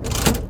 Lever4.wav